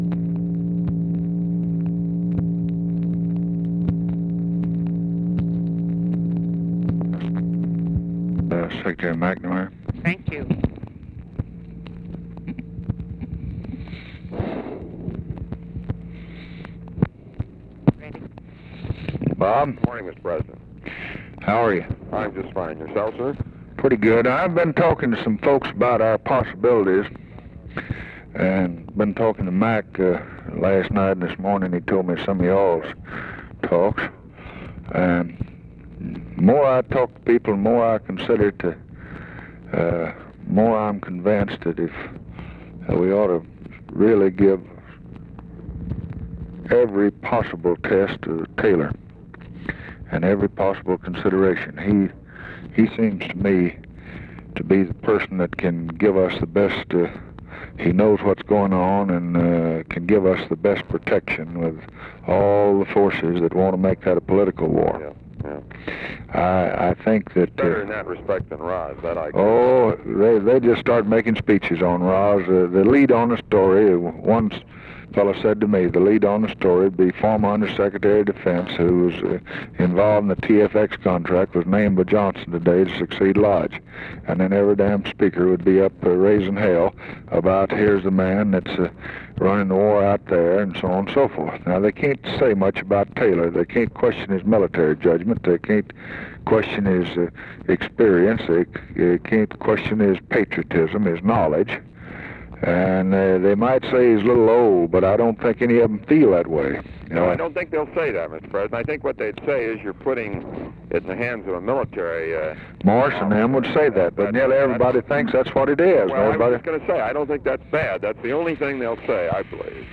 Conversation with ROBERT MCNAMARA, June 16, 1964
Secret White House Tapes